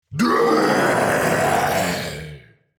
Free Fantasy Sound Effects Download.
Demon-king-lord-long-shout-3.mp3